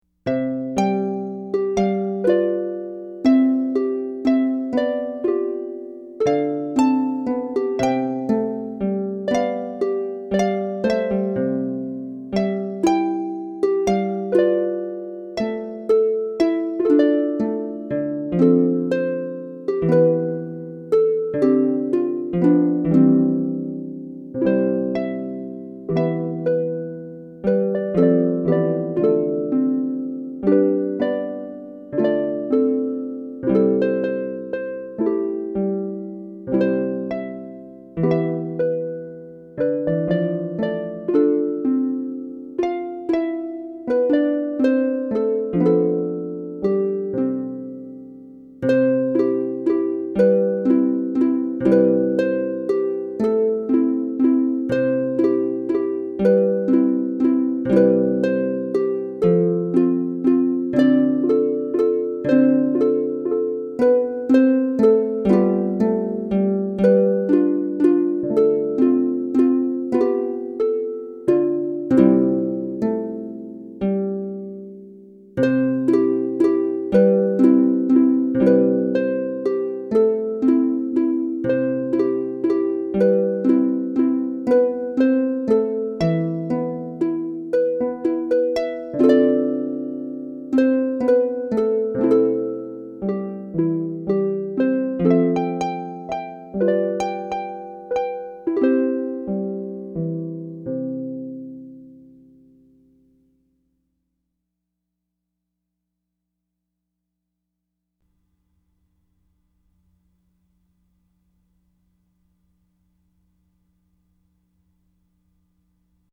for solo lever or pedal harp